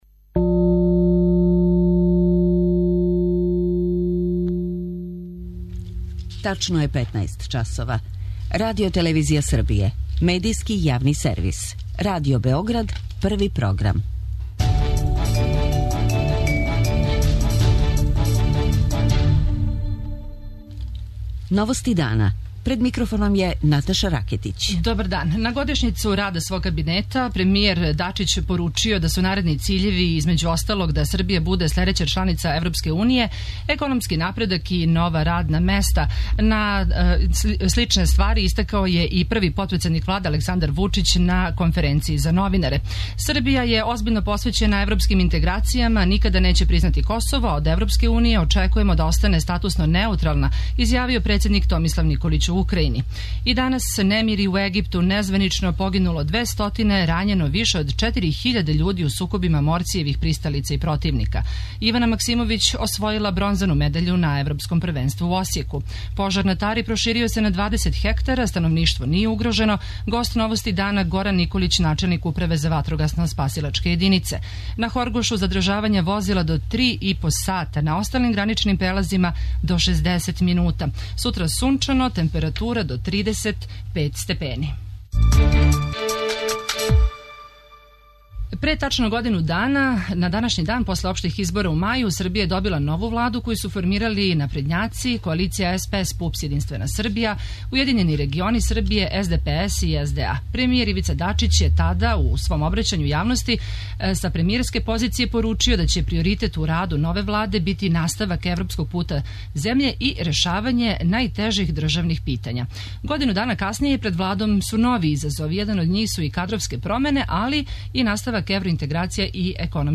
Гост Новости дана је Горан Николић, начелник Управе за ватрогасно-спасилачке јединице Сектора за ванредне ситуације МУП-а.